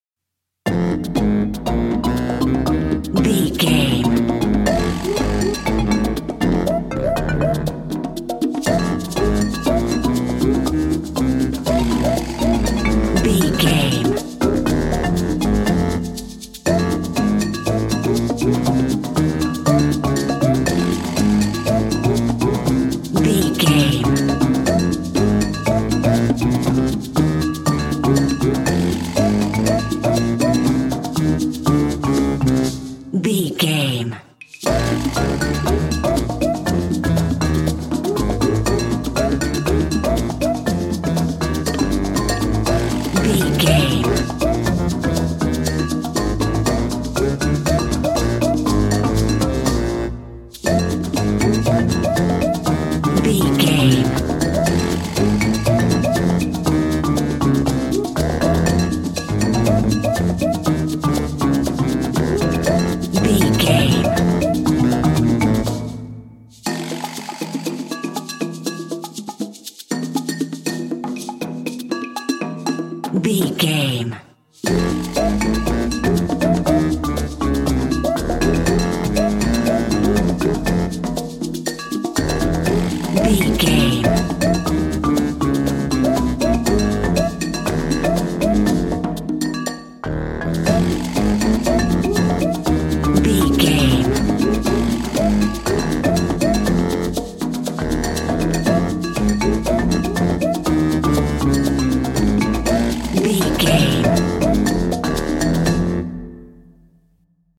A wacky/zany instrumental track with a groovy jungle vibe.
Aeolian/Minor
big band
electronic
synth
baritone
sax
trumpet
piccolo
marimba
percussion
bouncy
energetic
funky
joyful
congas
bongos
playful
brass